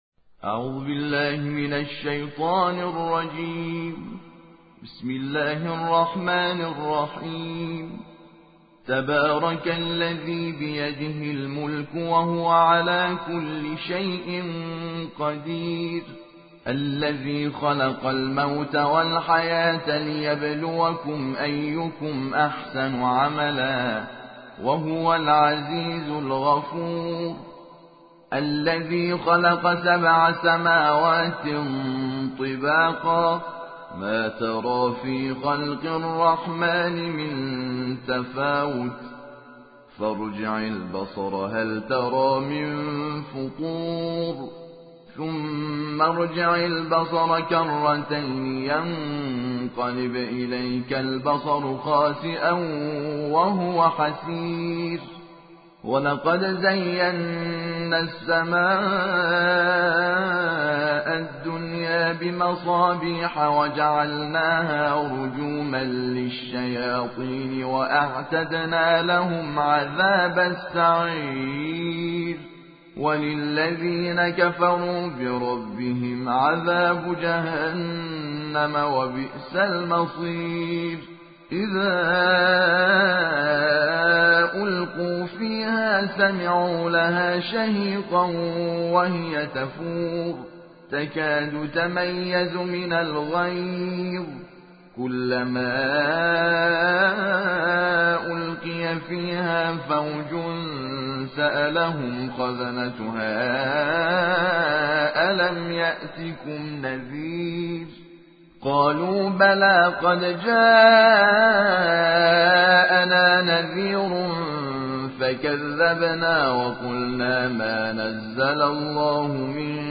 ترتیل جزء سی ام - حامد شاکر نژاد